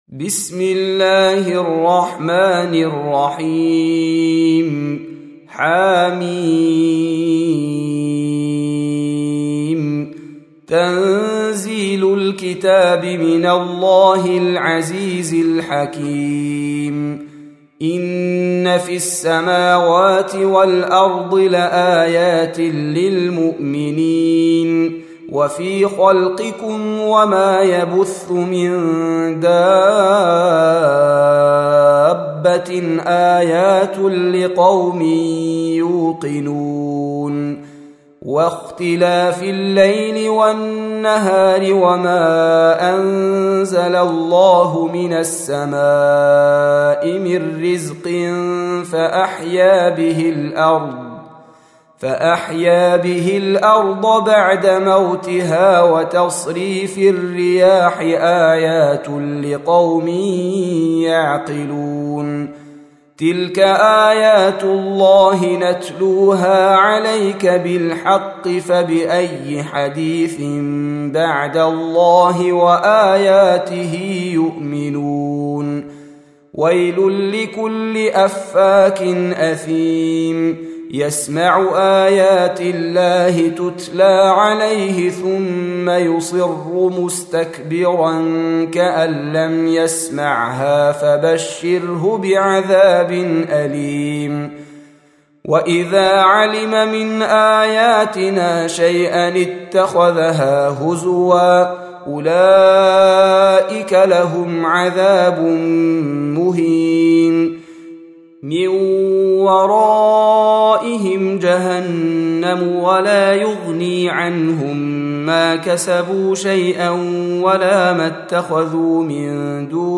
Surah Repeating تكرار السورة Download Surah حمّل السورة Reciting Murattalah Audio for 45. Surah Al-J�thiya سورة الجاثية N.B *Surah Includes Al-Basmalah Reciters Sequents تتابع التلاوات Reciters Repeats تكرار التلاوات